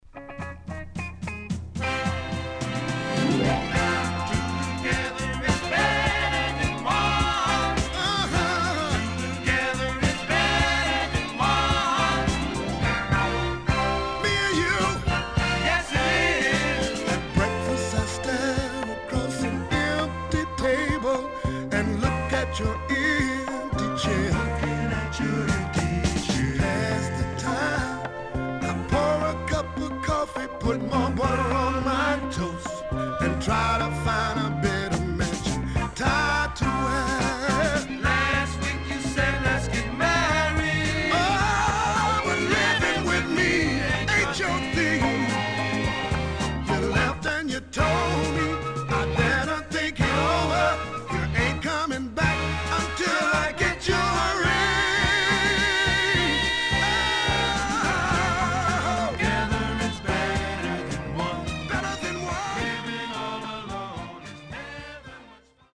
HOME > SOUL / OTHERS
名門黒人Vocal Group74年作。
SIDE A:少しノイズ入りますが良好です。